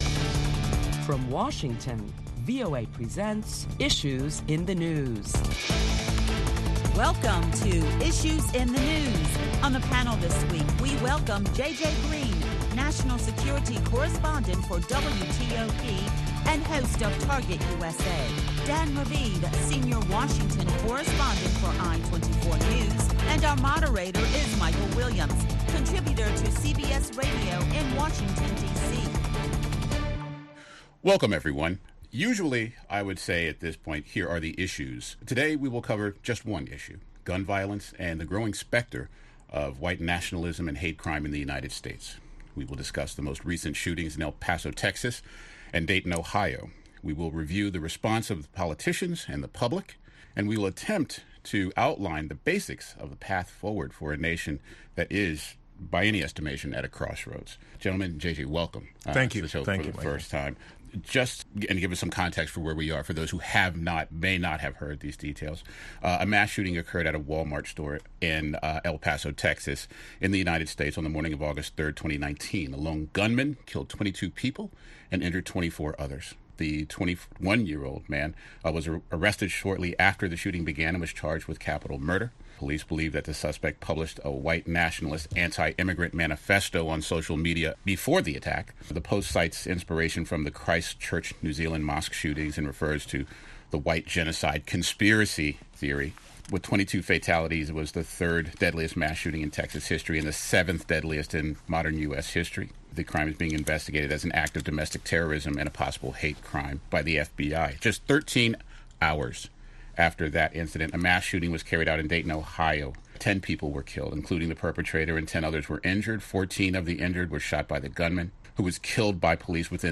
Listen to a panel of prominent Washington journalists as they deliberate the various reactions to the latest U.S. shootings and a look at what’s ahead with gun control.